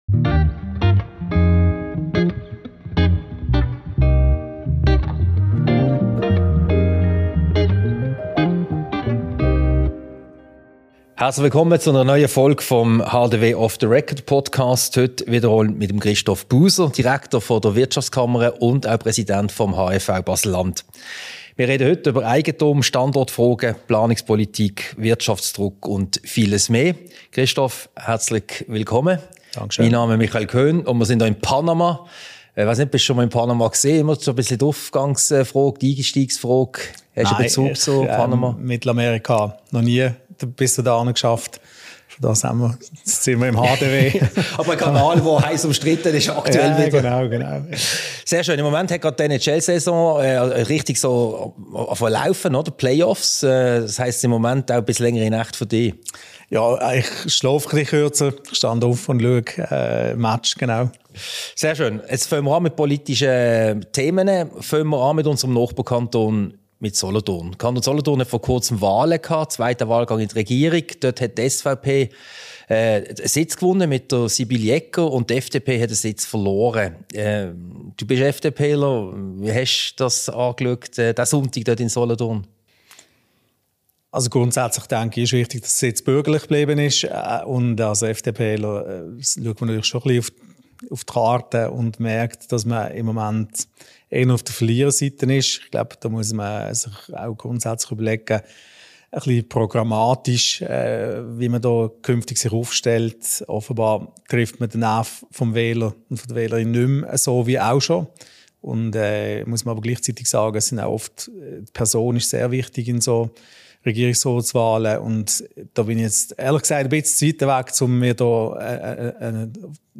Diese Podcast-Ausgabe wurde als Video-Podcast im Sitzungszimmer Panama im Haus der Wirtschaft HDW aufgezeichnet.